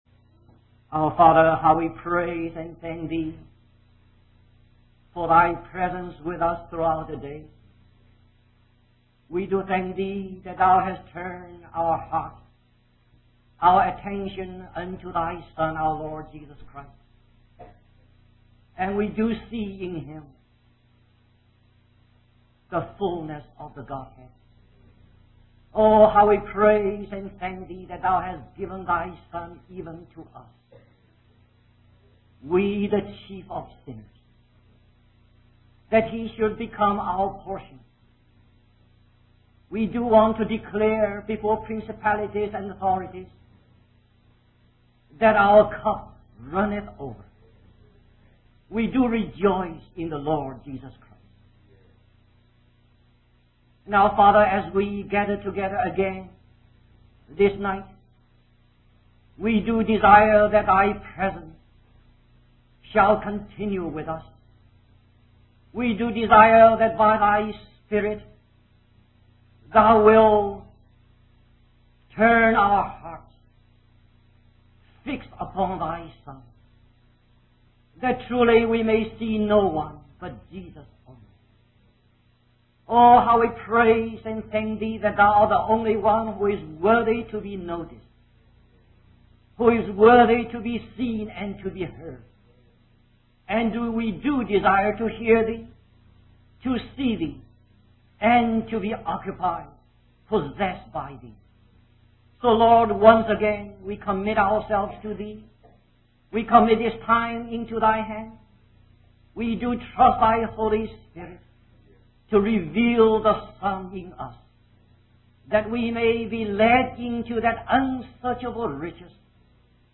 In this sermon, the speaker emphasizes the impossibility of achieving salvation through human effort. He shares his personal experience of being burdened by sin and trying to reform himself through reading the Bible, prayer, and church attendance.